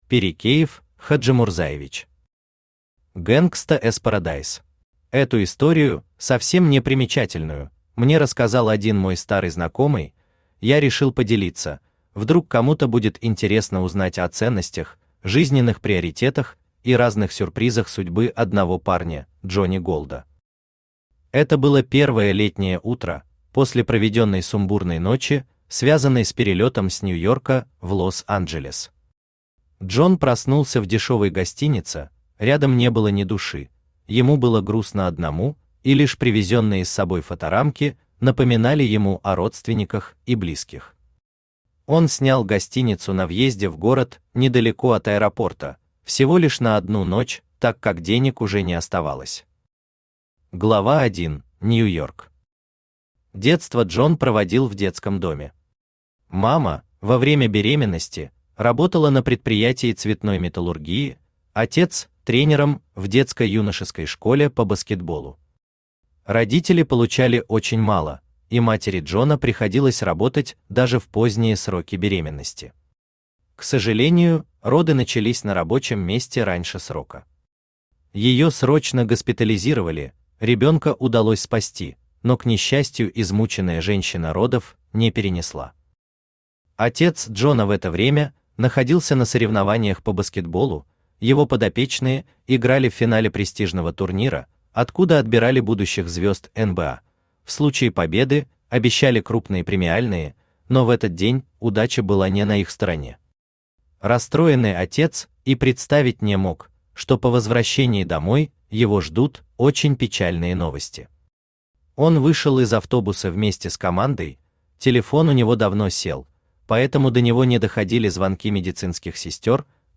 Aудиокнига Gangsta's Paradise Автор Пирекеев Рифат Хаджимурзаевич Читает аудиокнигу Авточтец ЛитРес.